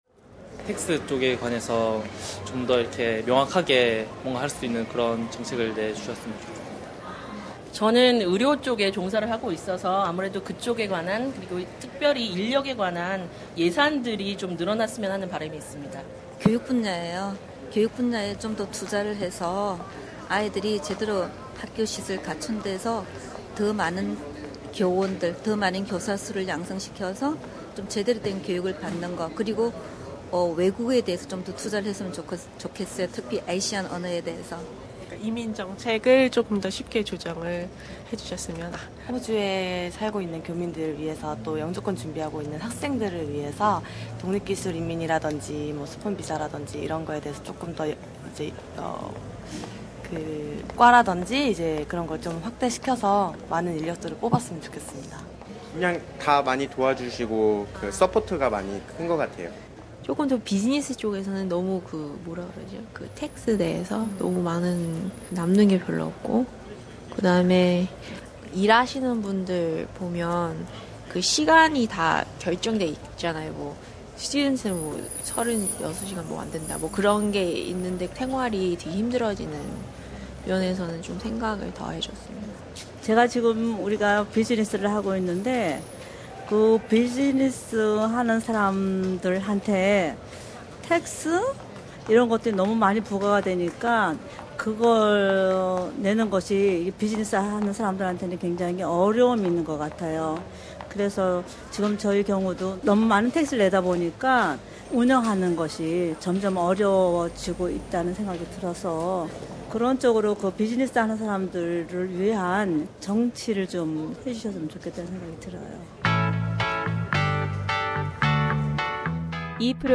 SBS Radio Korean Program conducted the voxpop with the Korean speaking voters in the wake of the election results, who expressed their most concern over the policies on tax cut, education and medicare.